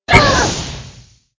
Angry Birds Space Bomb Launched
Perfect For Unblocked Sound Buttons, Sound Effects, And Creating Viral Content.